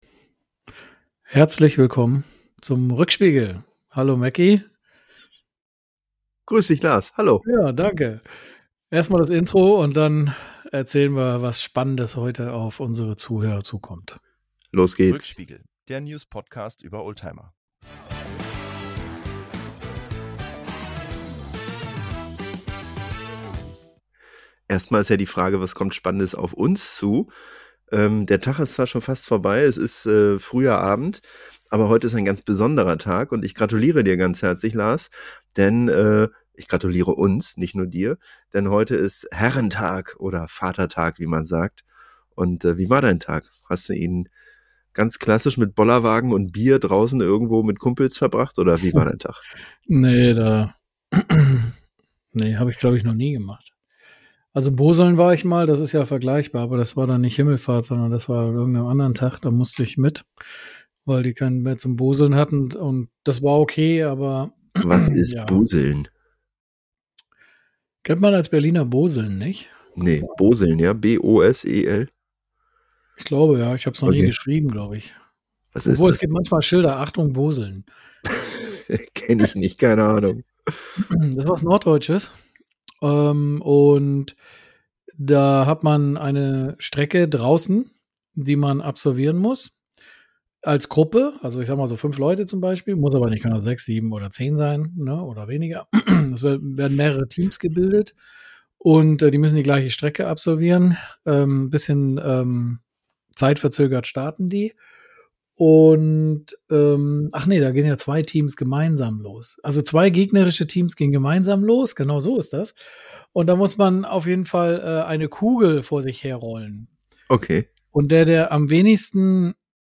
Ein launiger Vatertags-Talk über alte Autos, große Touren, Familienrituale und skurrile Taktiken im Grenzverkehr – zum Schmunzeln, Erinnern und Weitererzählen.